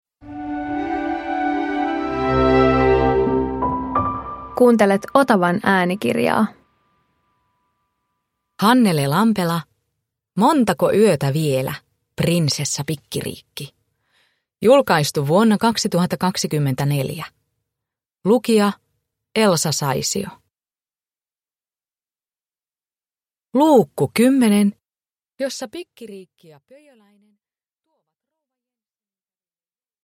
Montako yötä vielä, Prinsessa Pikkiriikki 10 – Ljudbok